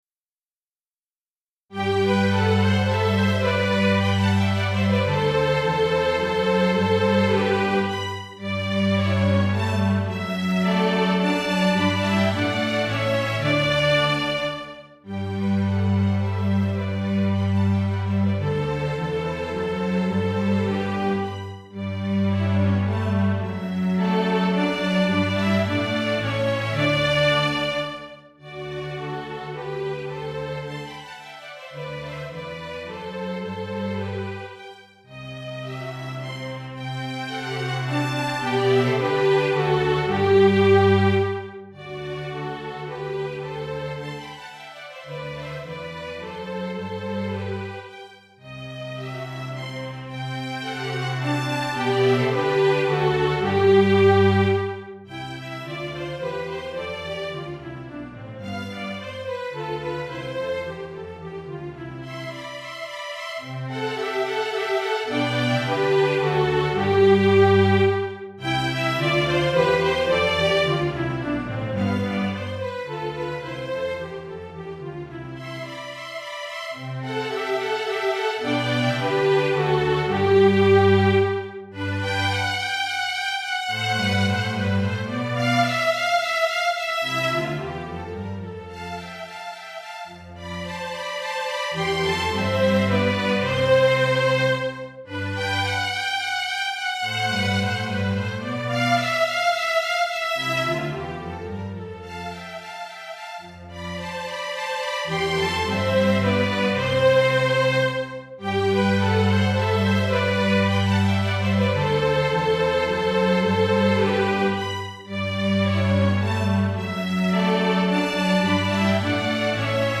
Répertoire pour Musique de chambre - Violon 1
Violon 2
Alto